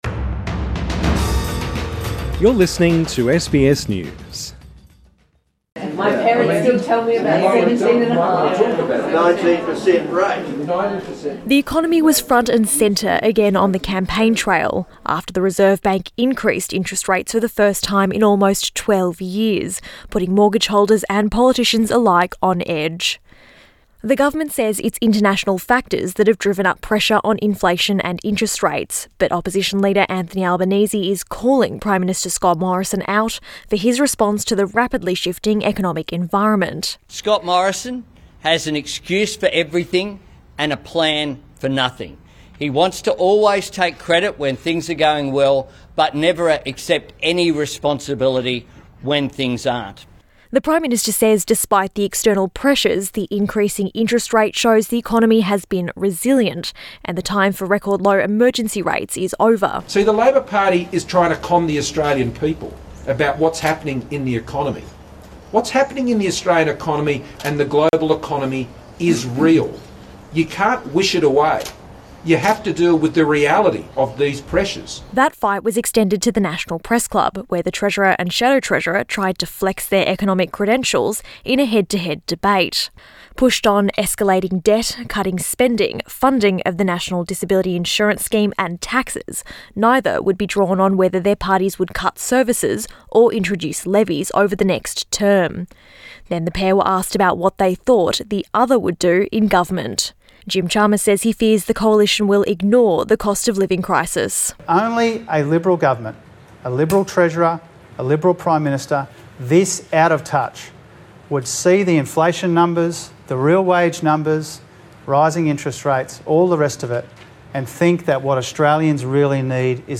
The two men vying to be treasurer have faced one another at the National Press Club.